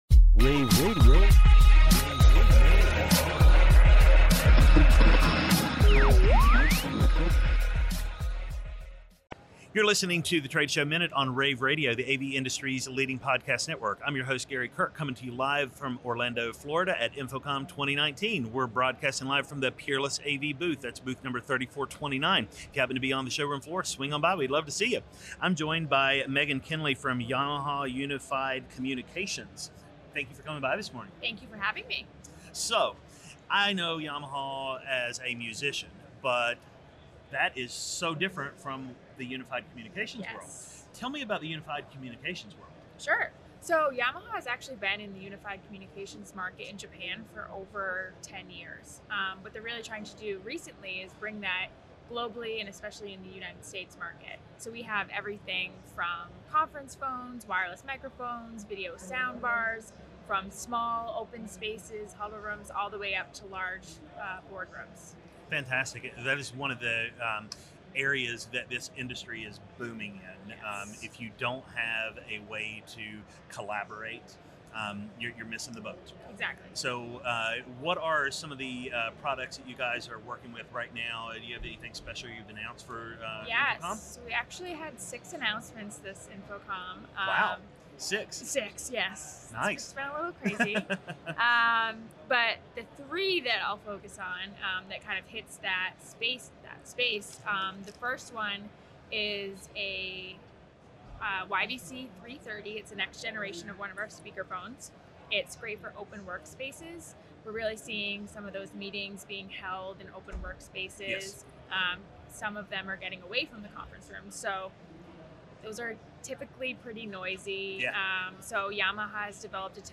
June 14, 2019 - InfoComm, InfoComm Radio, Radio, The Trade Show Minute,